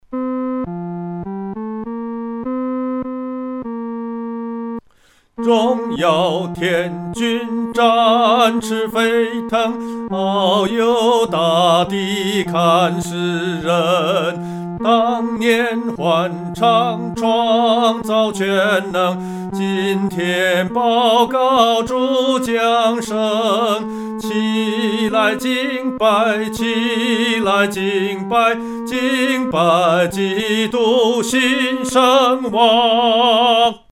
独唱（第三声）